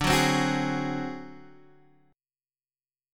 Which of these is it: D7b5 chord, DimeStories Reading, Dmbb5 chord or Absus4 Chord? D7b5 chord